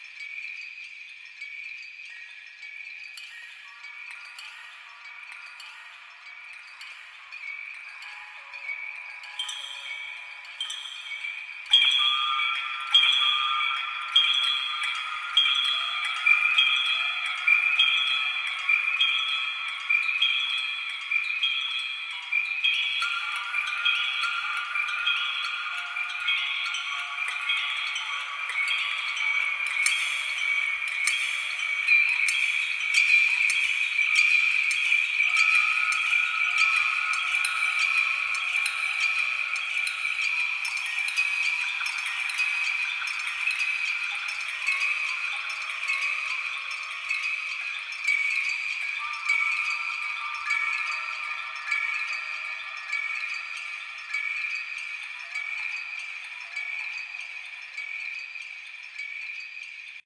ocean.ogg